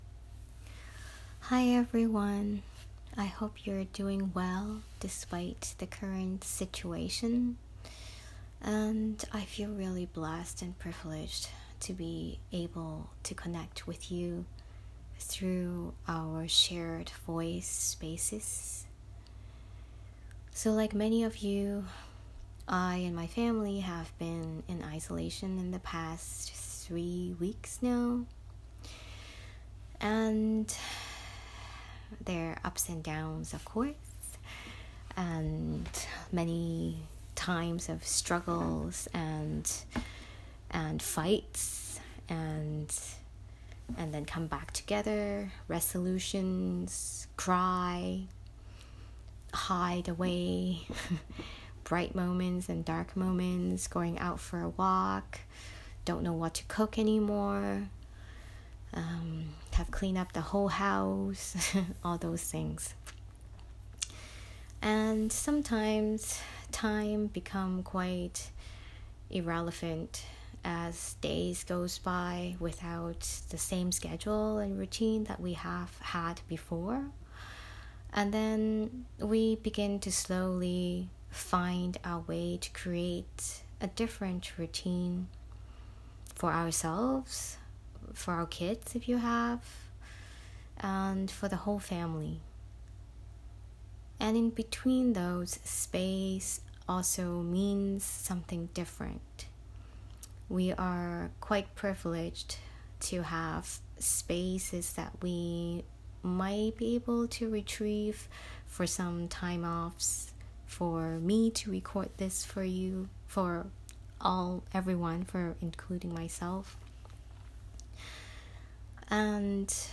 Meditation
meditation7avril2020.m4a